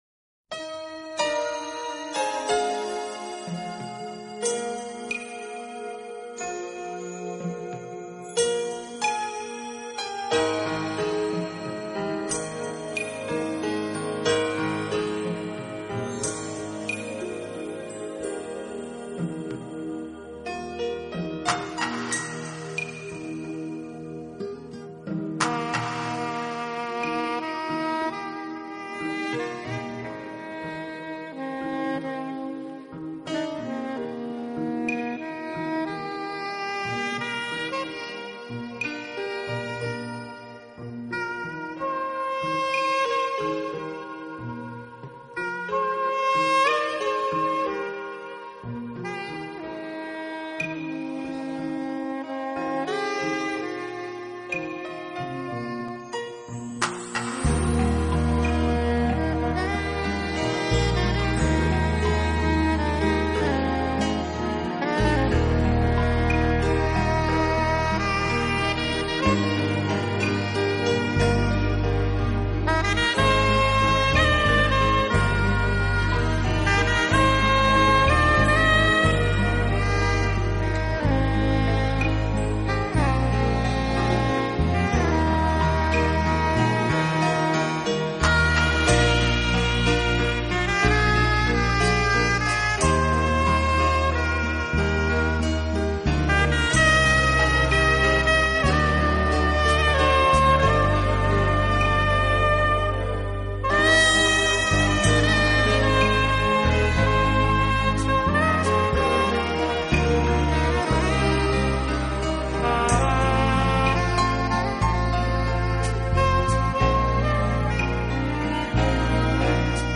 【爵士钢琴】